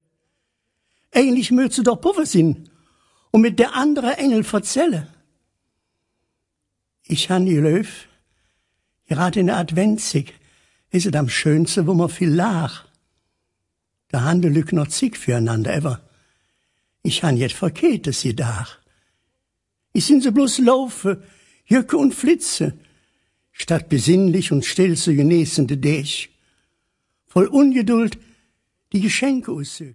Weihnachtsleedcher und Verzällcher in Kölscher Mundart